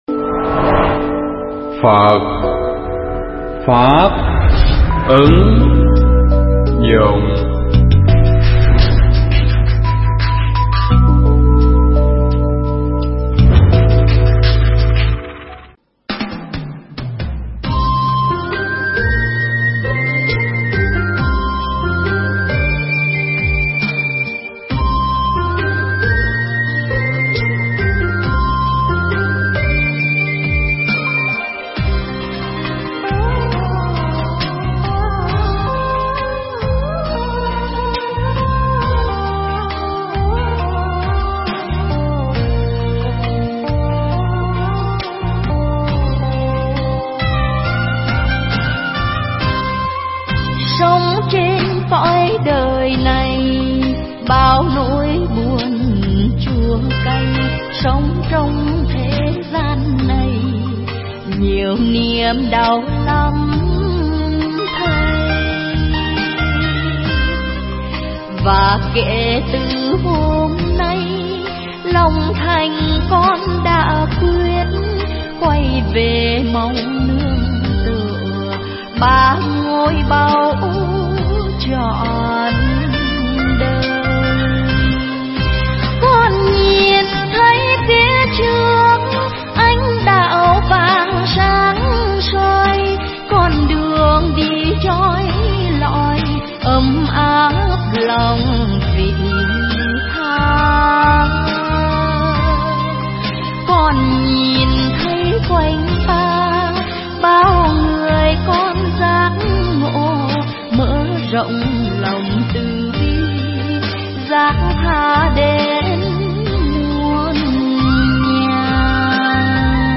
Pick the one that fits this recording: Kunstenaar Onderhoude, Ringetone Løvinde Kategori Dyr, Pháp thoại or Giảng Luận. Pháp thoại